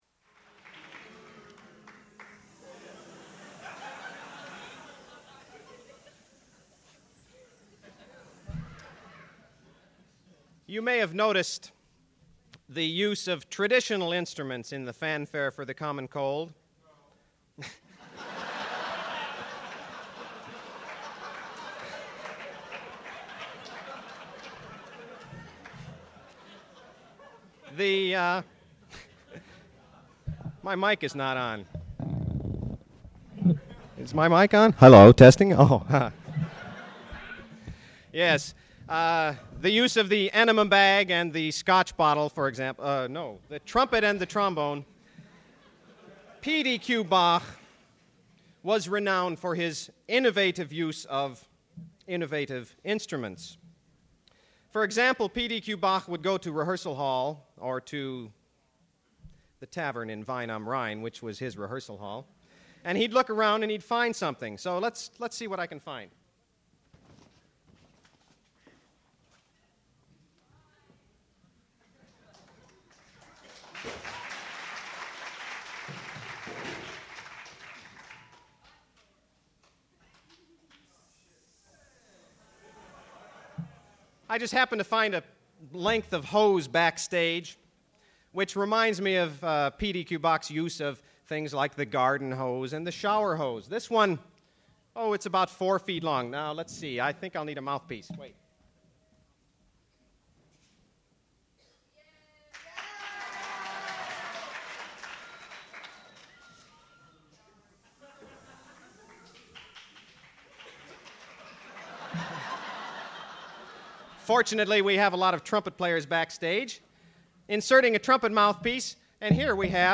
sound recording-musical
classical music
horn
trombone
trumpet
A Halloween Concert : Music of P.D.Q. Bach (1807-1742?)